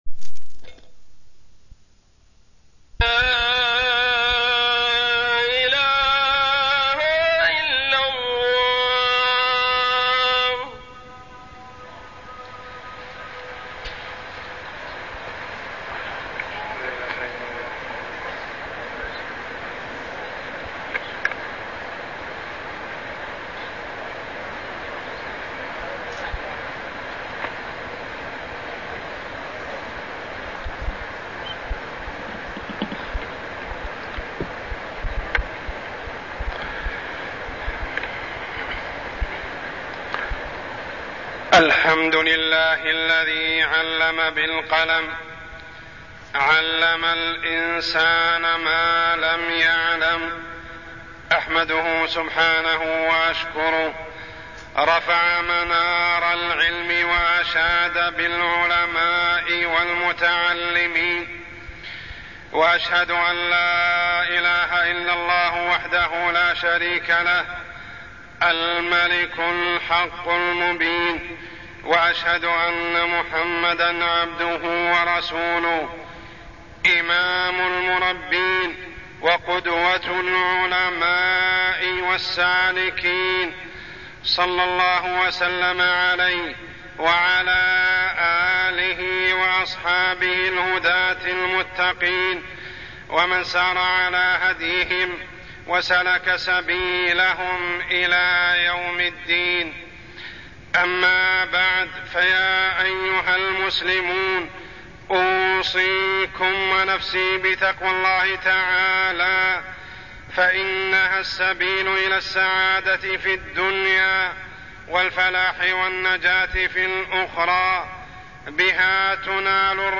تاريخ النشر ٢٣ جمادى الأولى ١٤٢٠ هـ المكان: المسجد الحرام الشيخ: عمر السبيل عمر السبيل نشر العلم والمعرفة